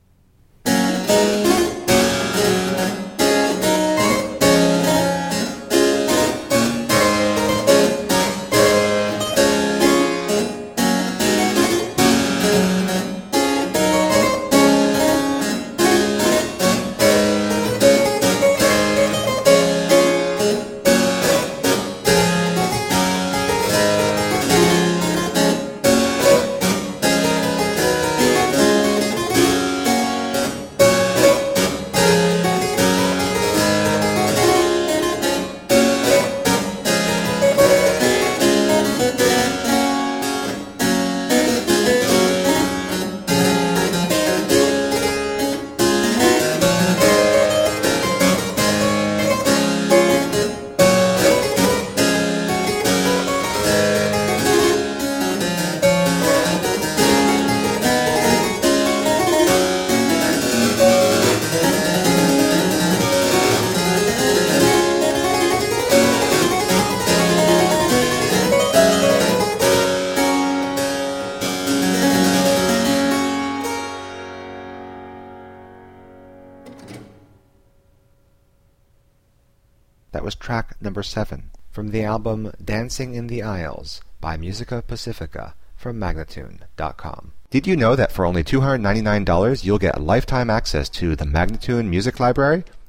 baroque violins
harpsichord